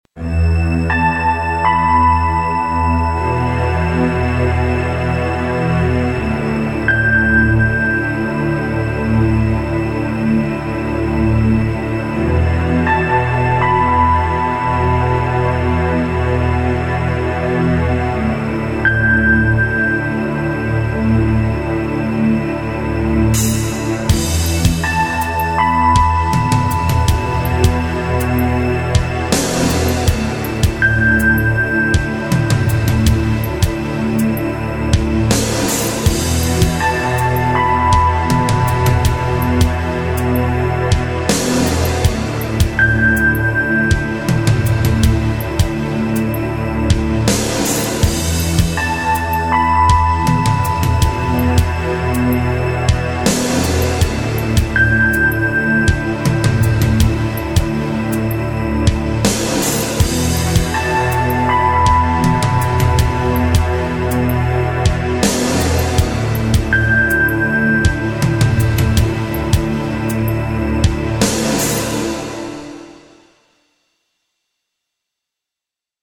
我们主要准备了阴暗且诡异的音乐。